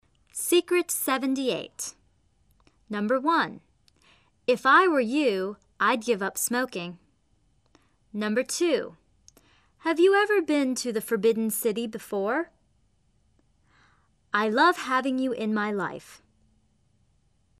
秘诀78    唇齿相依摩擦音的发音技巧
英音=美音［f  v］